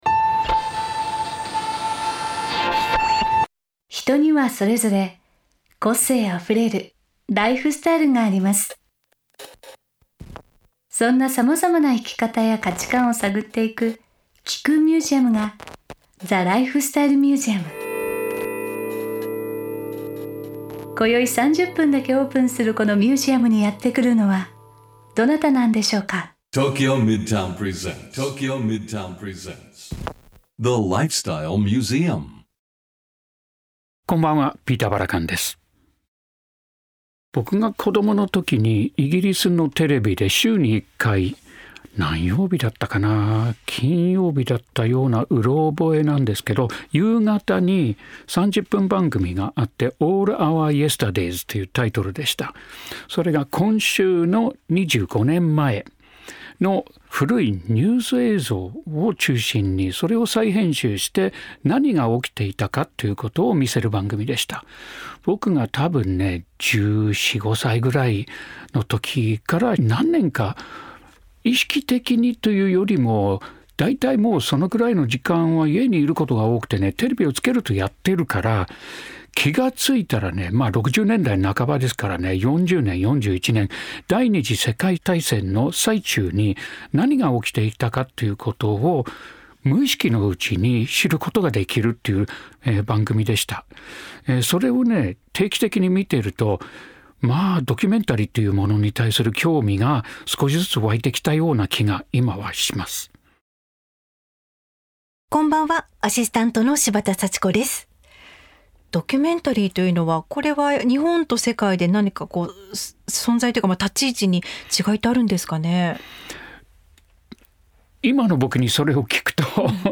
ピーター・バラカン氏がメインパーソナリティーとなり、毎回様々なゲストを迎えて生き方や価値観を探っていくゲストトーク番組。